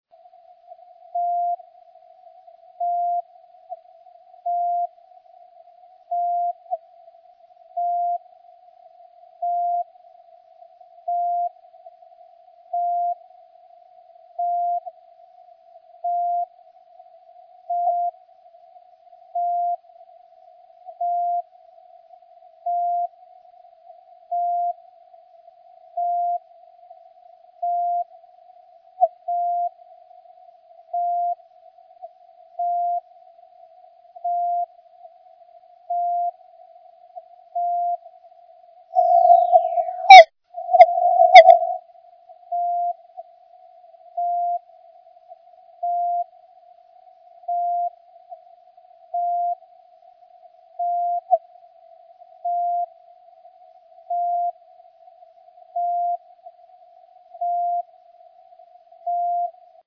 a strong signal that is swept across the spectrum as well as a weak
signal (120 dB down) which is on-off keyed.
The weak signal and the sliding huge signal
file the spurs are very strong but attenuated due to the